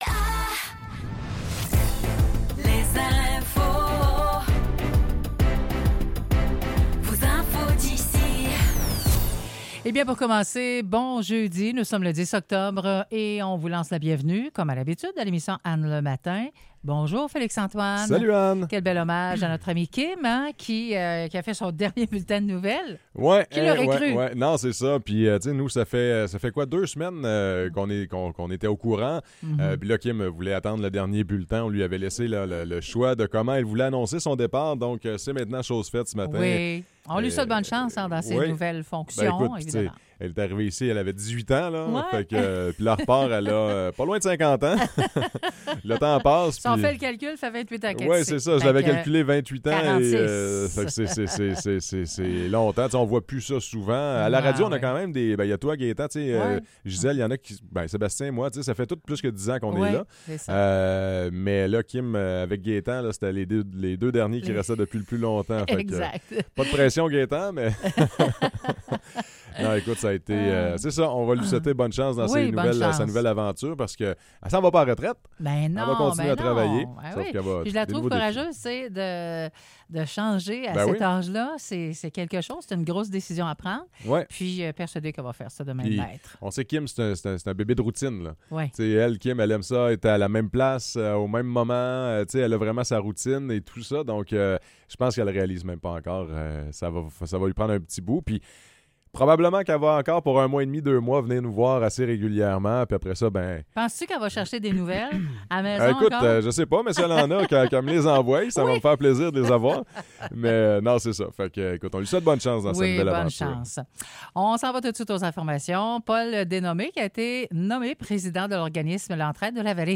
Nouvelles locales - 10 octobre 2024 - 9 h